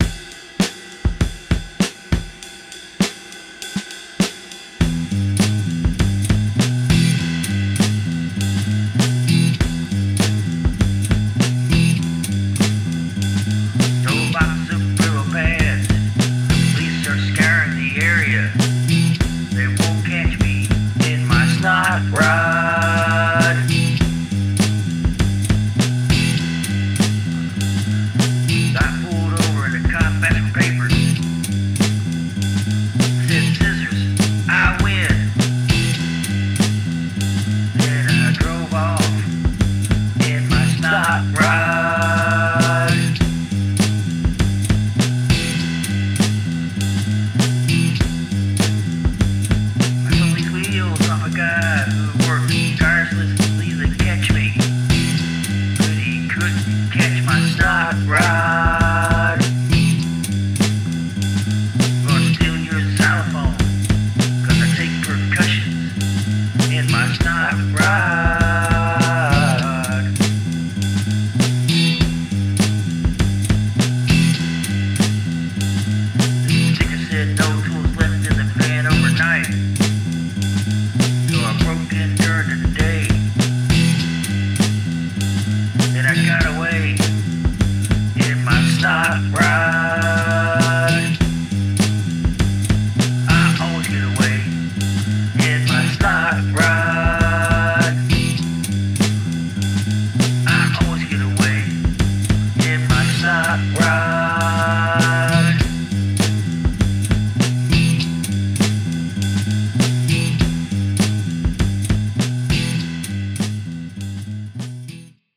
Reminds me of the height of humour alt-rock from the 90s.